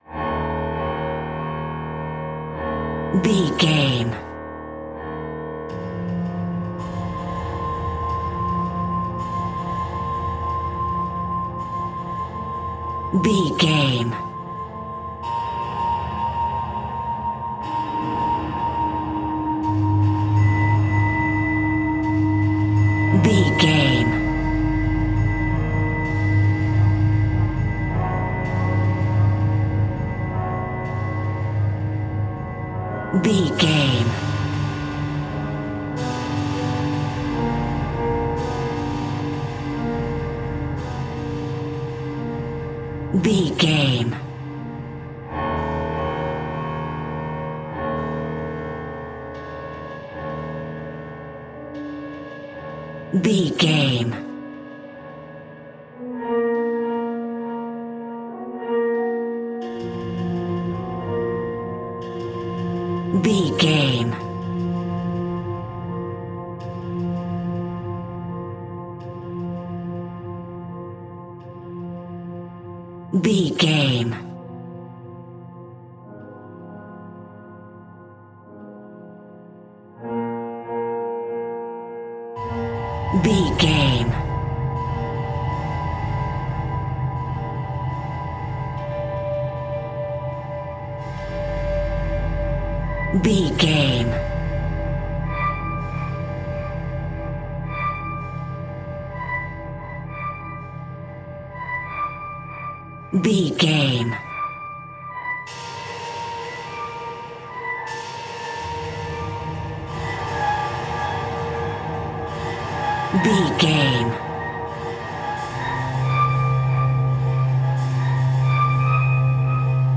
Aeolian/Minor
E♭
ominous
dark
haunting
eerie
Scary Piano